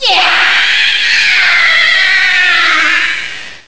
[ 13 ] The sped-up eerie scream is also used for The Minish Cap when Vaati , its main antagonist, opens the Bound Chest .
MC_BoundChest_Spirits_Scream.wav